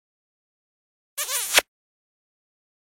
Cartoon Kiss sound effect
Thể loại: Hiệu ứng âm thanh
Description: Cartoon Kiss sound effect là hiệu ứng âm thanh nụ hôn hoạt hình, tiếng hôn kêu lên ngọt lịm, đây là tiếng nụ hôn ngọt ngào, lãng mạn của các nhân vật hoạt hình thể hiện tình cảm với người mình yêu, tiếng hôn vang lên ngọt lịm là âm thanh ngọt ngào, tình cảm.
Cartoon-kiss-sound-effect-www_tiengdong_com.mp3